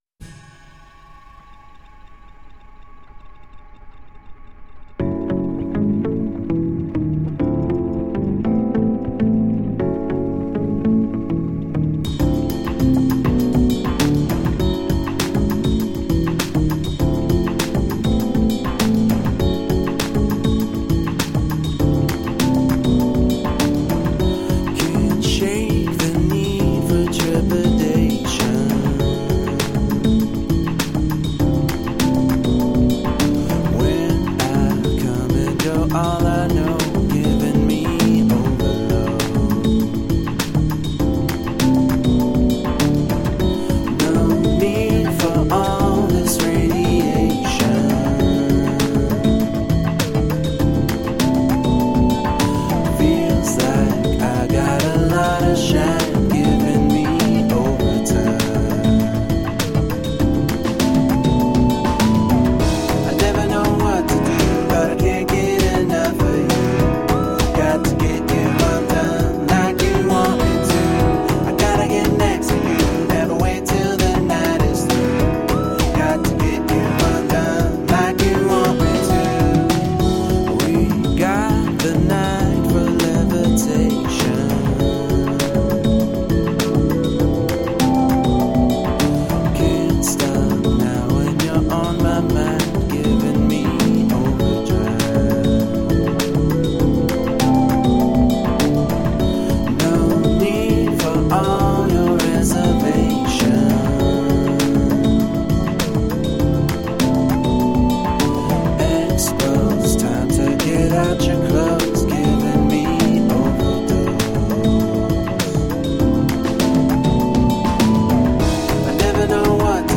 Soulful electro pop.
Tagged as: Electro Rock, Rock, Electro Pop